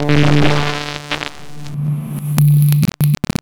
Abstract Rhythm 06.wav